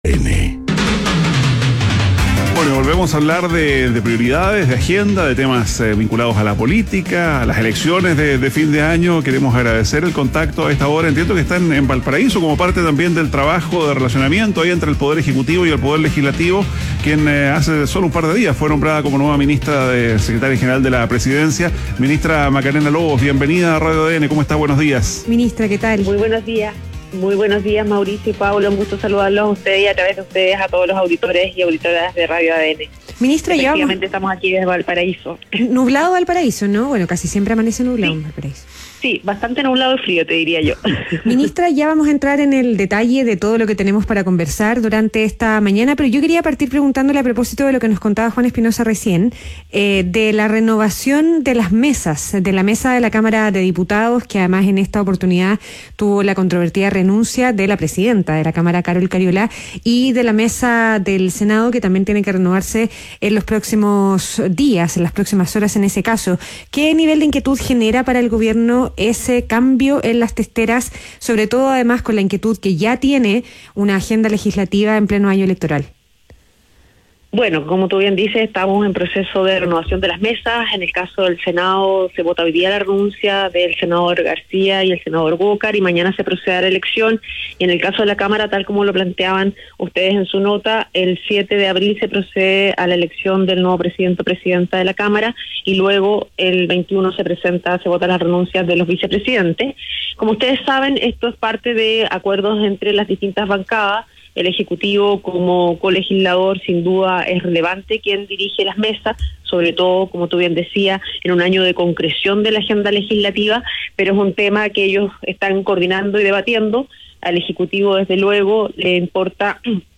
ADN Hoy - Entrevista a Macarena Lobos, ministra de la Secretaría General de la Presidencia